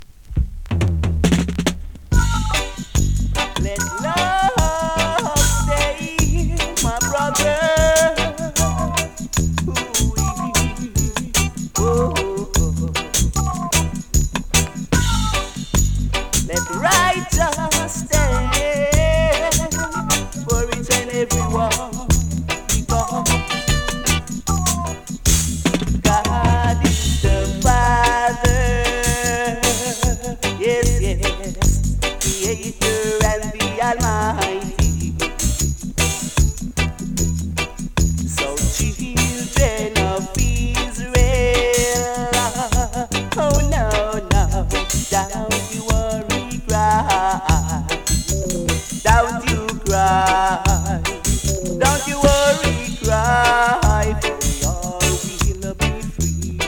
スリキズ、ノイズかなり少なめの